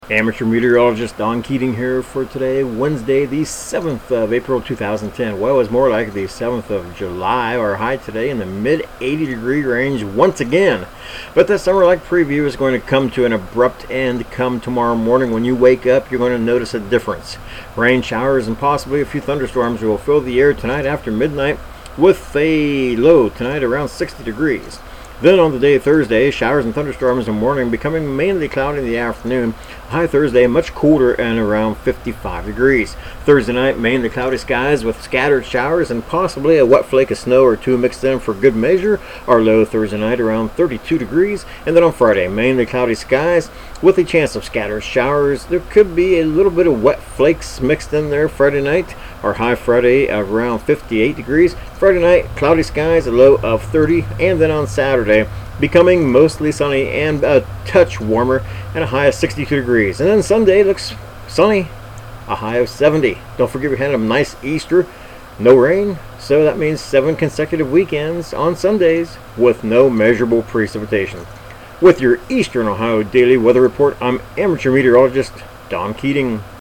Tags: Weather Ohio Newcomerstown Forecast Report